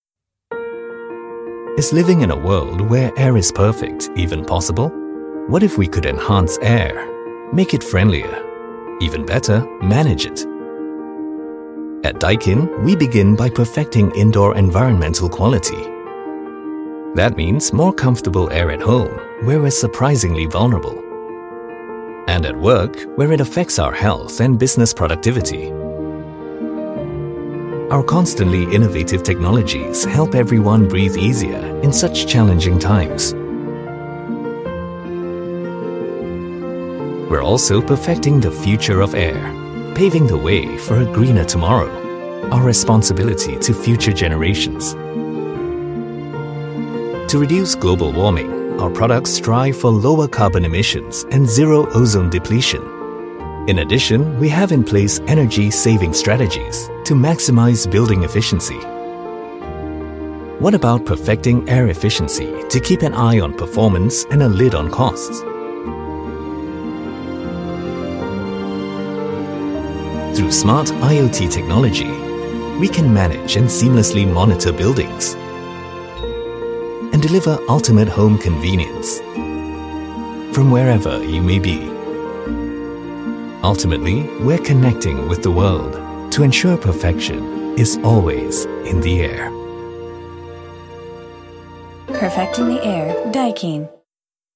English (Singapore)
Narration
Baritone
WarmAuthoritativeConversationalFriendlyDarkEngagingAssuredReliable